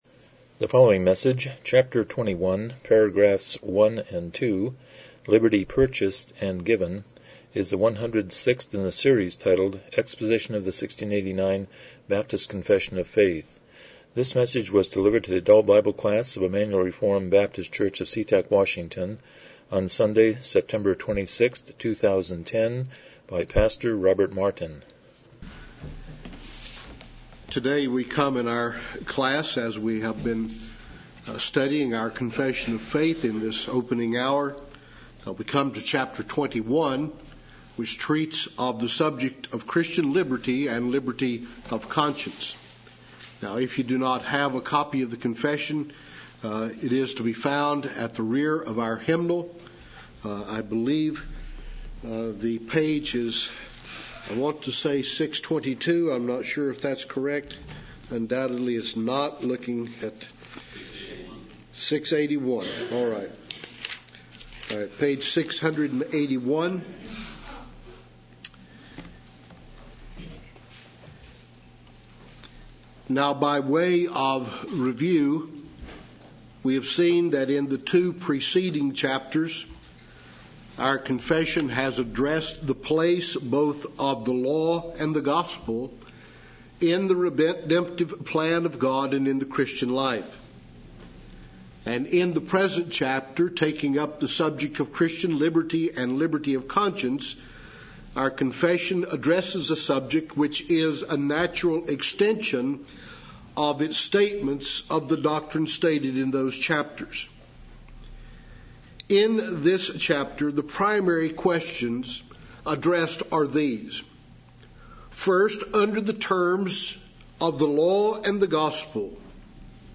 Series: 1689 Confession of Faith Service Type: Sunday School « The Good News and the Bad News